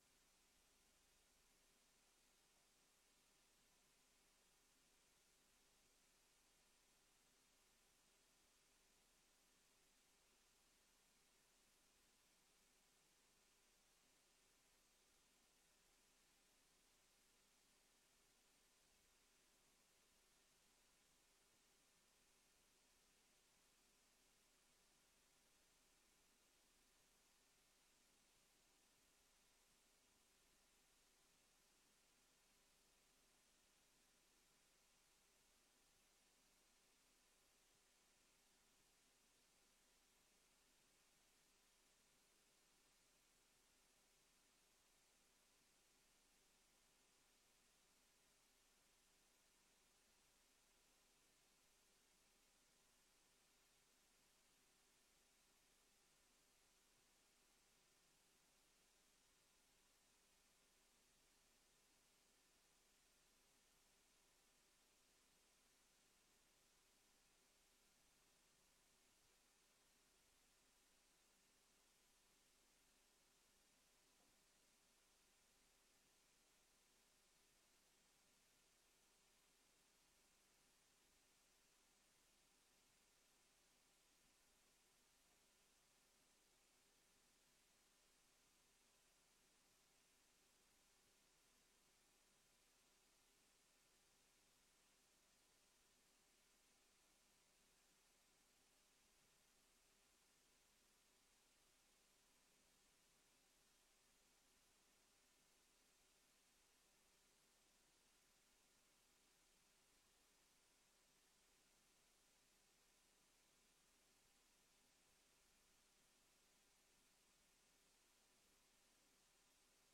Gemeenteraad 19 december 2024 20:00:00, Gemeente Woerden
Download de volledige audio van deze vergadering